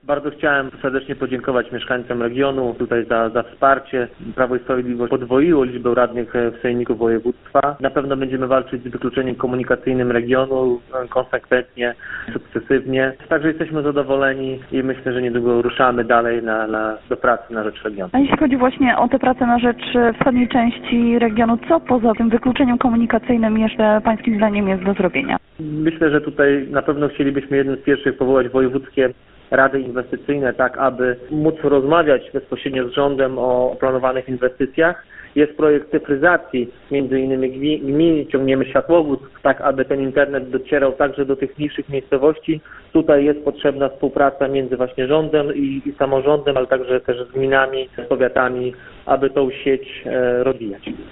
W rozmowie z Radiem 5 Czemiel  podziękował wszystkim za udział w wyborach i przedstawił plany na najbliższy czas. To – jak mówi – przede wszystkim walka z wykluczeniem komunikacyjnym wschodniej części Warmii i Mazur.